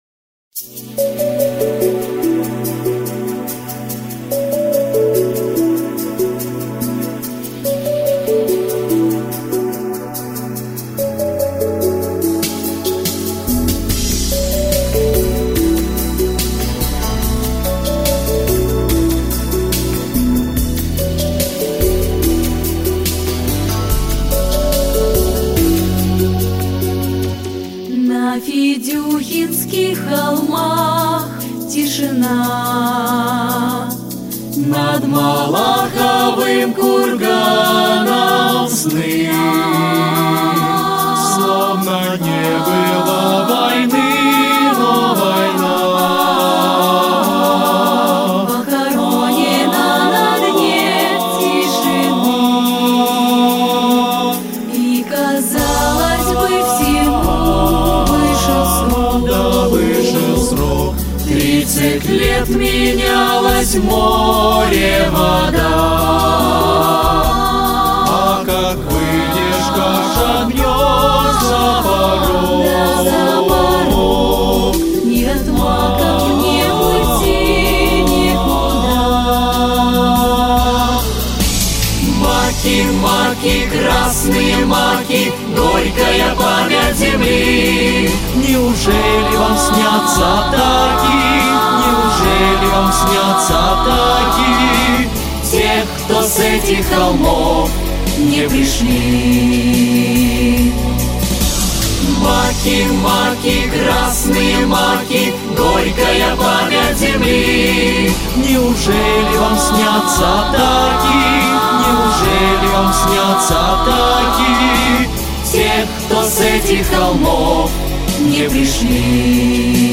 • Жанр: Детские песни
Детская песня